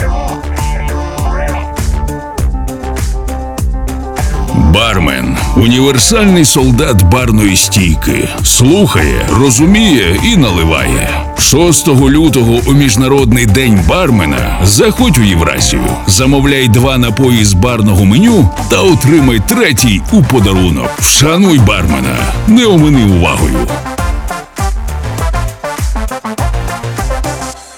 FireBrands – експерти зі звукового дизайну для радіо- і TV-реклами.